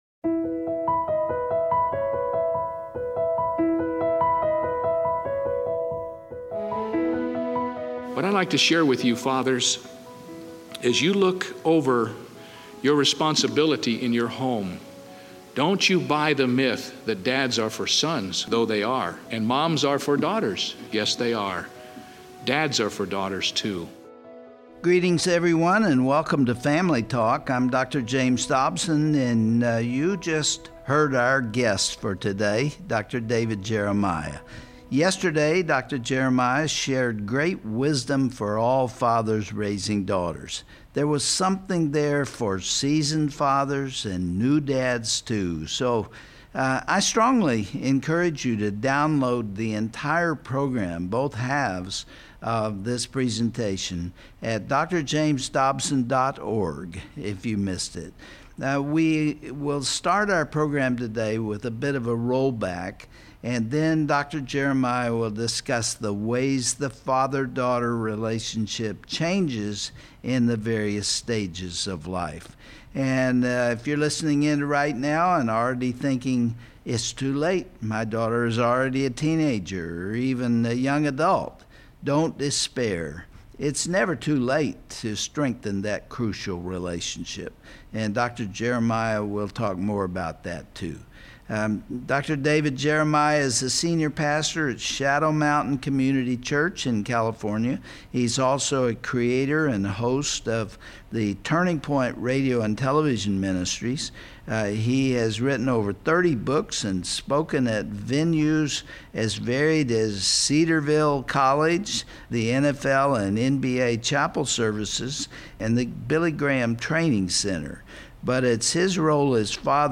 Absent and unloving fathers can cause lasting damage to their Daughters self-esteem. On this Family Talk broadcast, author and pastor Dr. David Jeremiah concludes his message about the critical bond between a girl and her dad. He looks back at his own mistakes as a father and addresses a young girls tendency to look for a man similar to her dad.